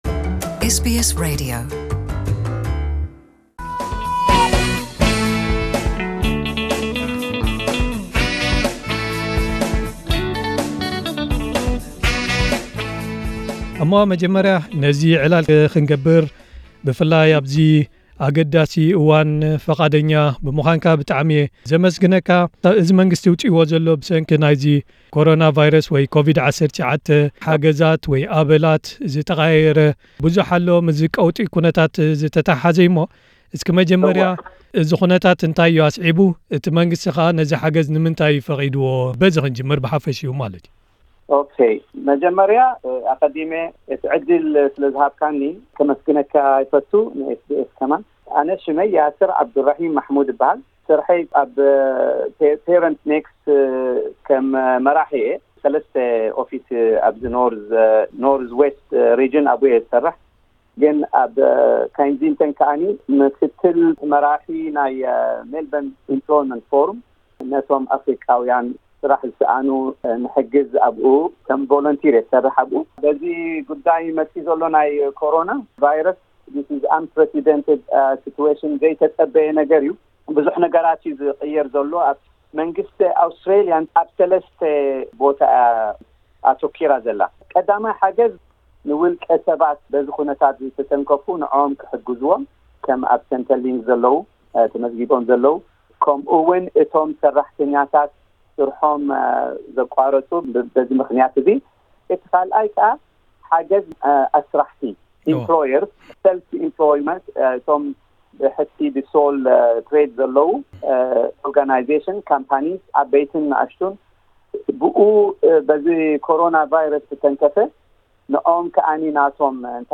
ደዊልና ኣዘራሪብናዮ ኣለና።